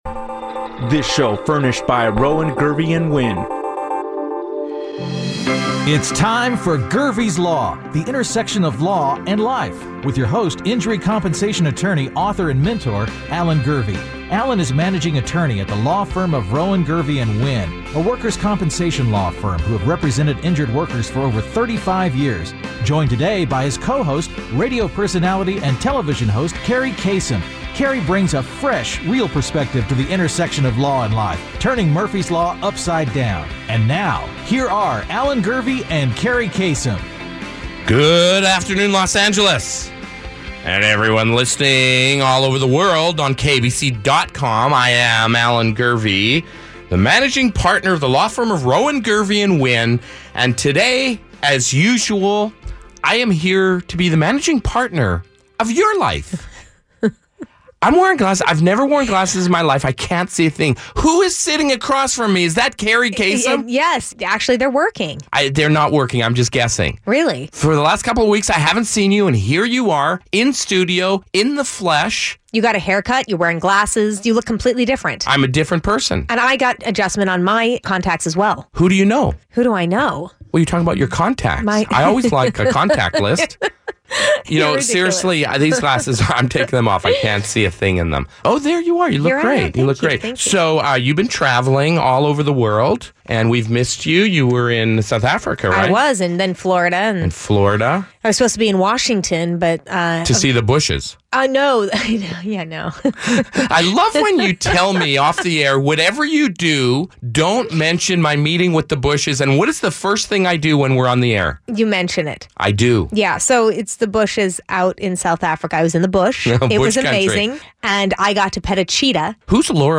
If you miss a show, join us on Itunes as we bring you Gurvey's Law from broadcast to podcast.